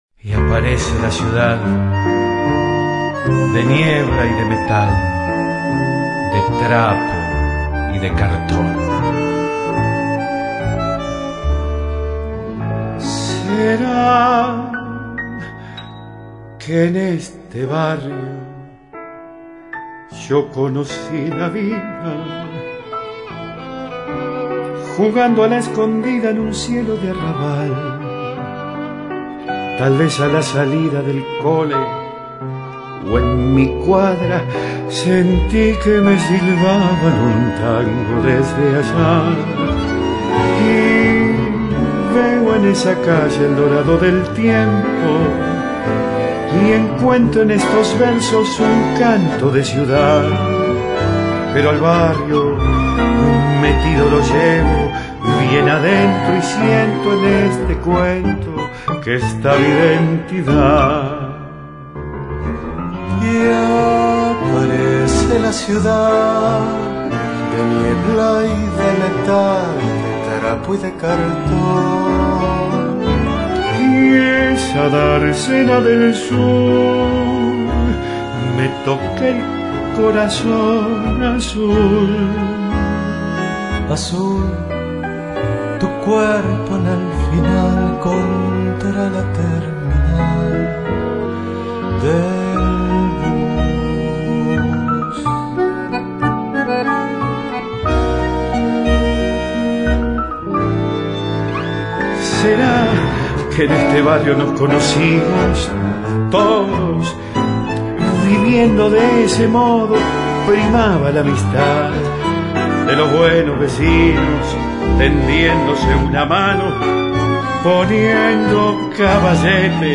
Latinas